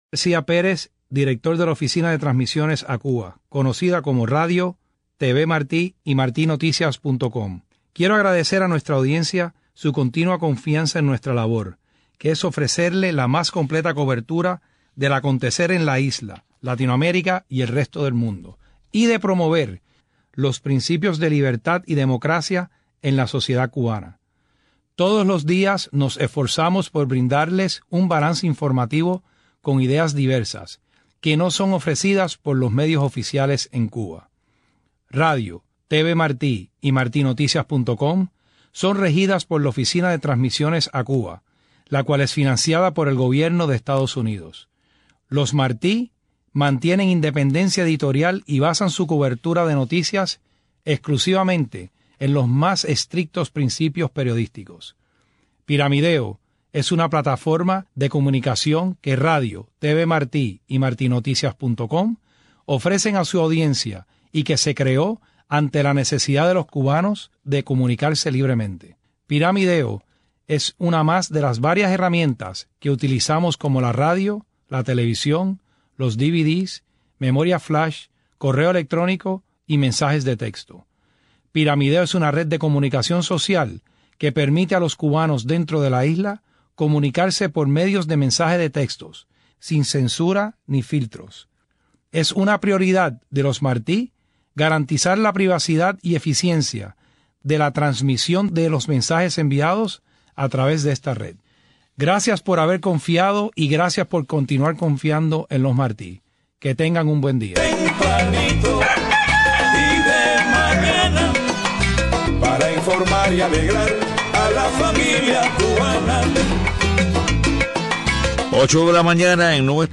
8:00 a.m. Noticias: Pide líder opositora venezolana María Corina Machado la renuncia del presidente Maduro. Cancilleres de UNASUR regresan a Caracas para reunión con sectores de la sociedad venezolana.